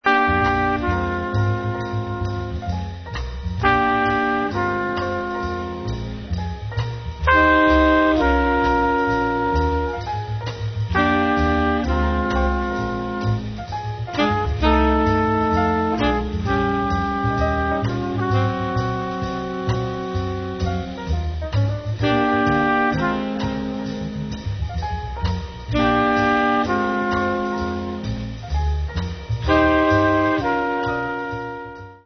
Les trompettistes